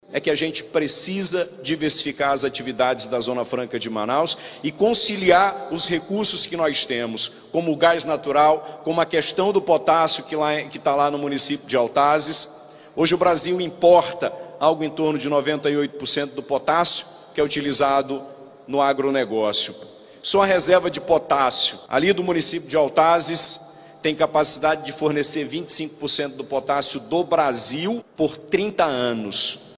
No discurso, o governador Wilson Lima (União Brasil), pediu que o governo federal reconheça a importância da ZFM.